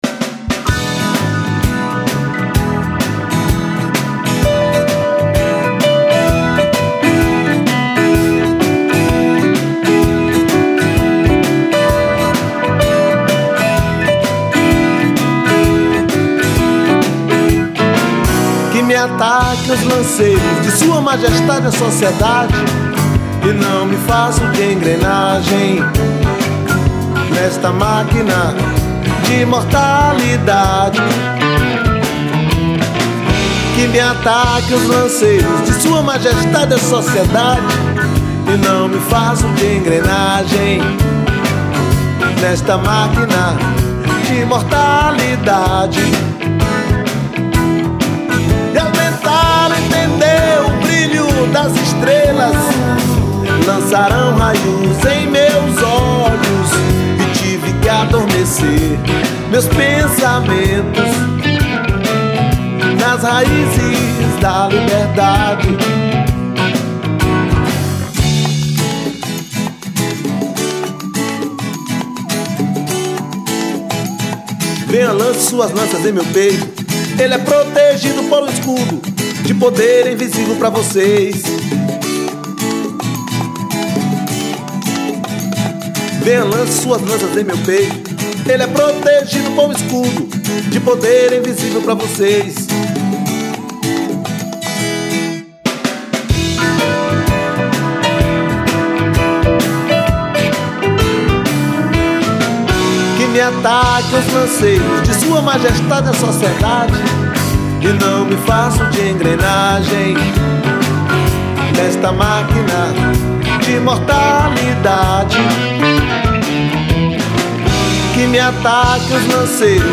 1335   03:57:00   Faixa:     Rock Nacional